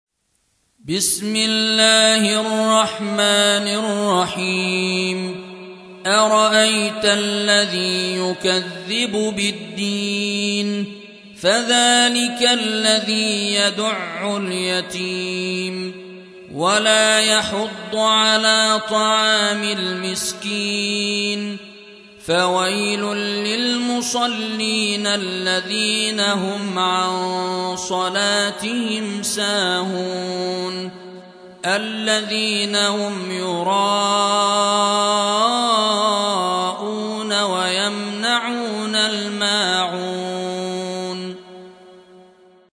107. سورة الماعون / القارئ